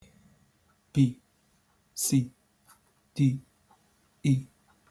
It's spoken clearly, with a neutral, slightly robotic tone. The pronunciation of each word is distinct, and the spacing between them is deliberate.
The audio is short and straightforward, lacking any musical accompaniment or background sounds.